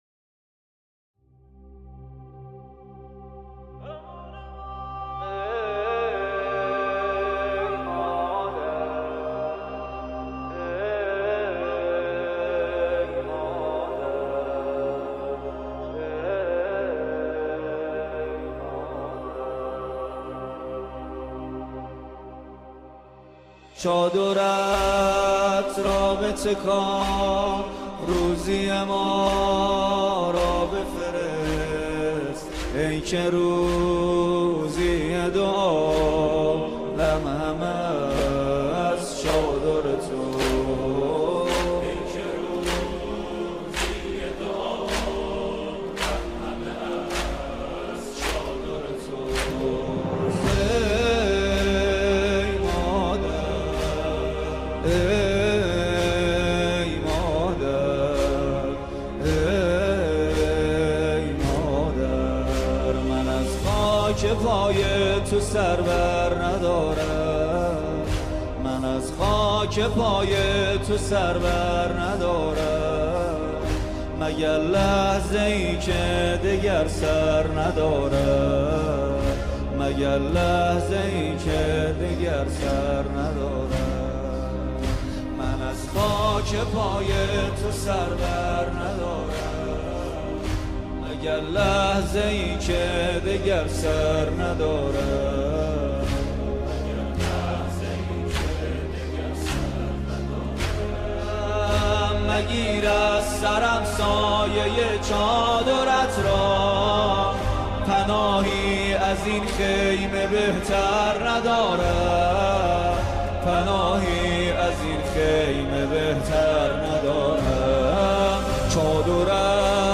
کلیپ زیبا از دسته عزاداری حضرت فاطمه (س)
دسته عزاداری فاطمیون بمناسبت ایام فاطمیه توسط هیئت روضه الزهرا برگزار شد